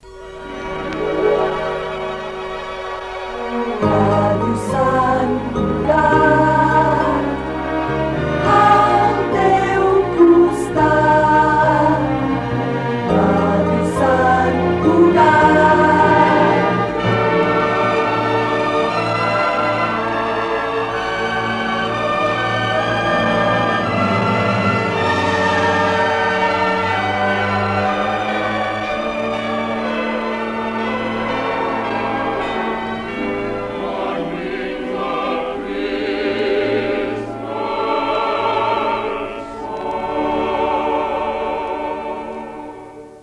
Indicatiu nadalenc cantat